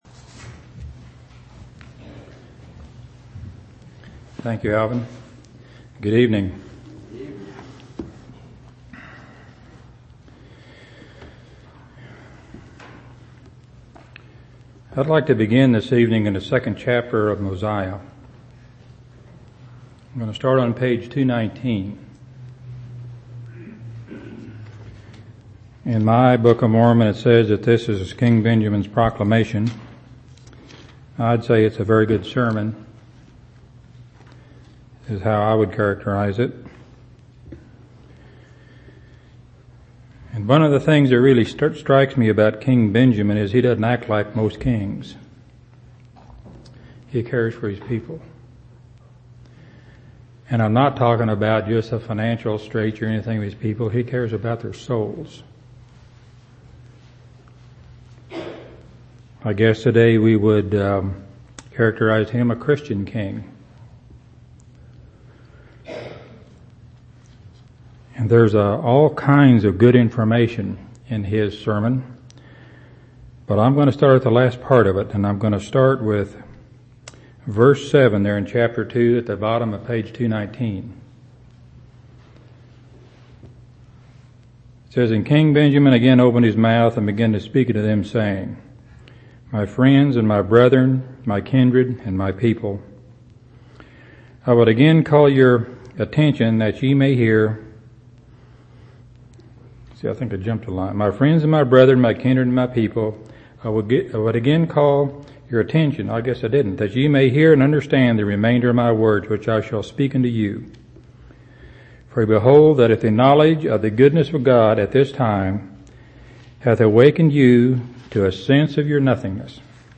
11/28/2004 Location: Temple Lot Local Event